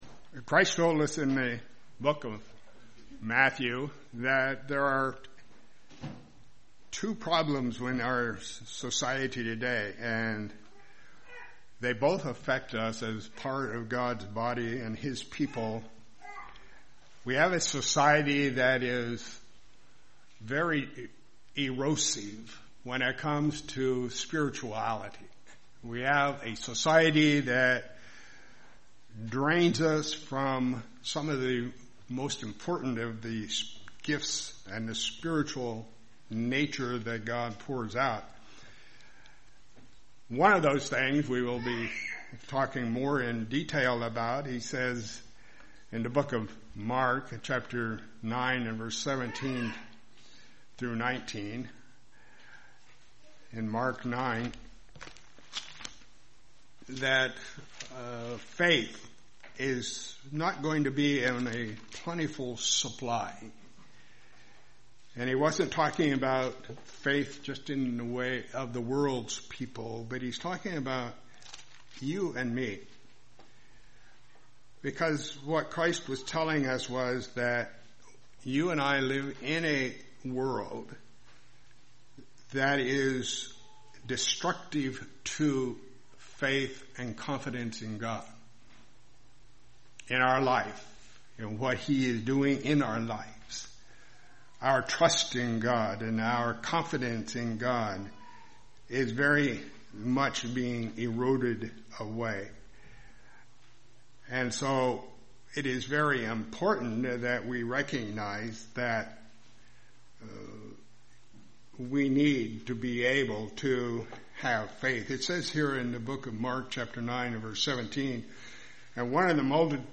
This sermon will cover the subject of faith and how it can be increased.